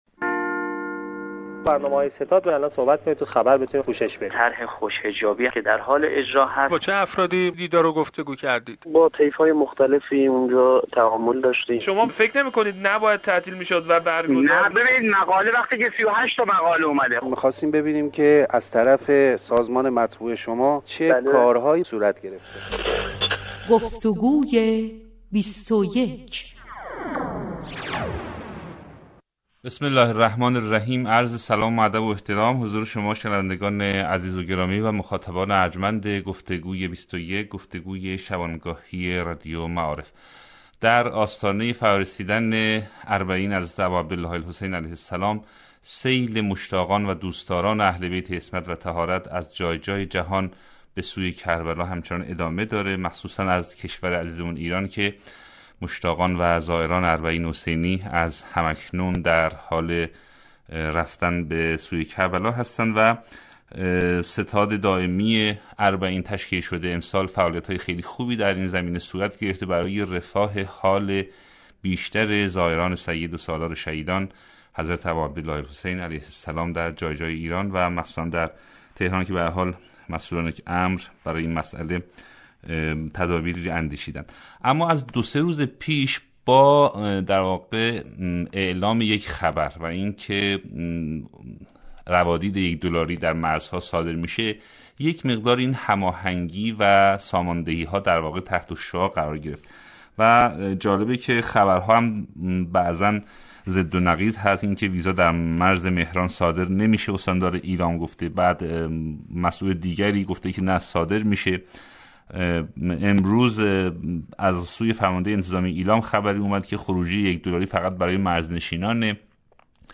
ویزای یک دلاری چه شد؟ گفتگوی 21 -.mp3